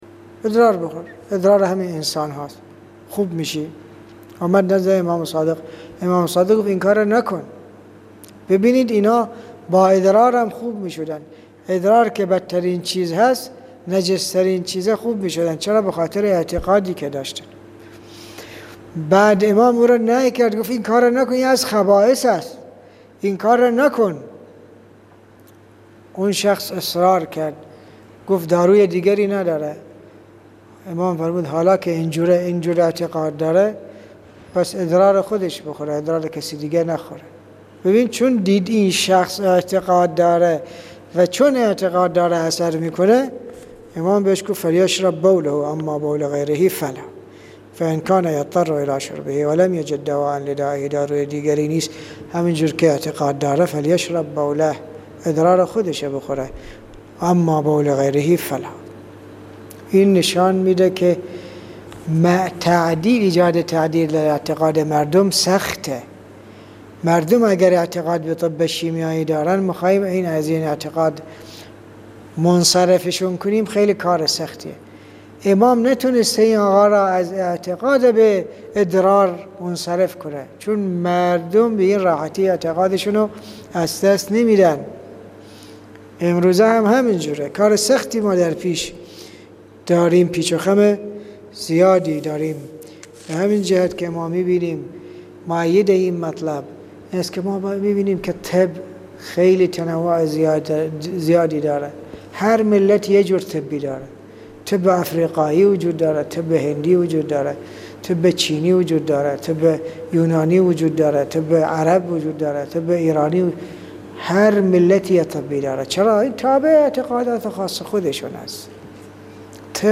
صوت تدریس جلد 2 ، جلسه 9